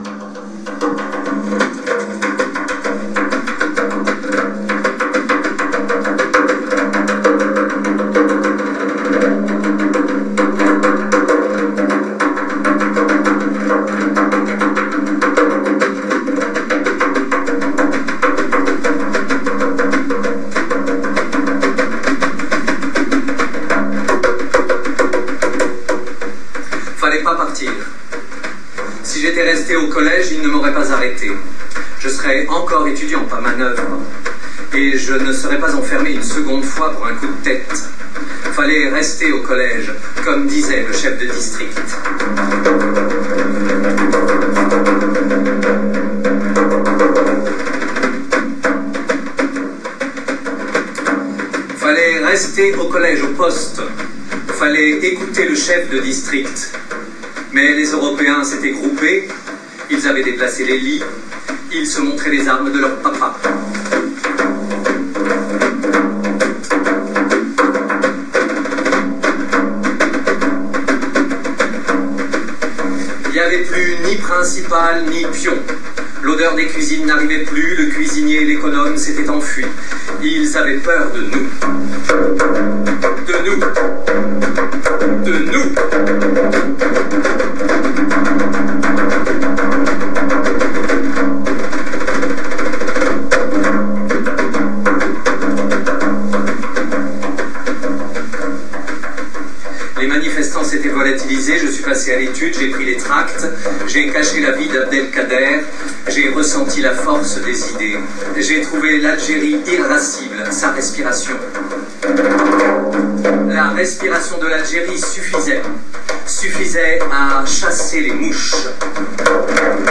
Kateb Yacine (extrait audio en public) http